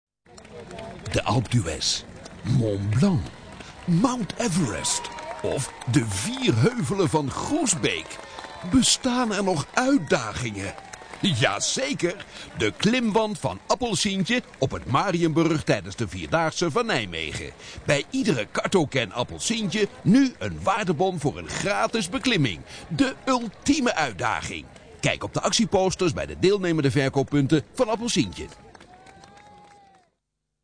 Luister naar voorbeelden van onze audiobewerking voor commercials.
De Stemselectie, tekstschrijven, muziek en geluidseffecten maken deel uit van het productietraject.